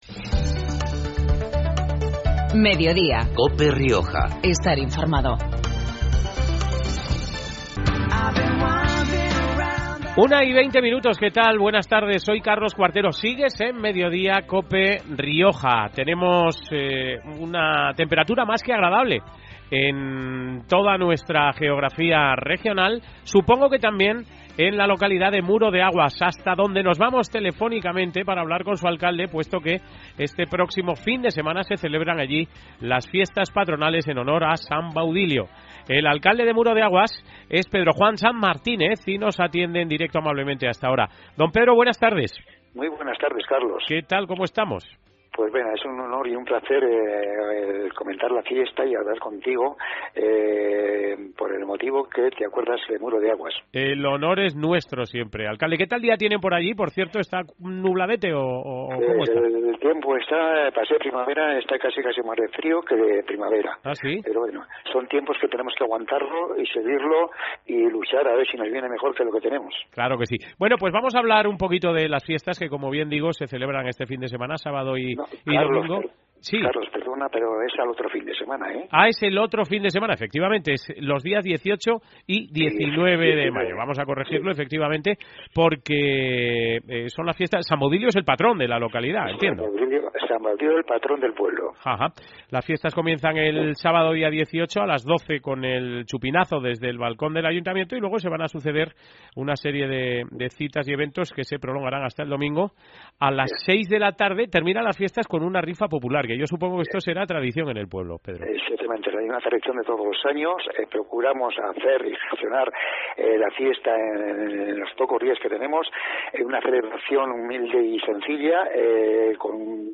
Mediodía en Cope Rioja Baja (miércoles, 8 de mayo. 13:20-13:30 horas). Hoy con Pedro Juan Sanz Martínez, alcalde de Muro de Aguas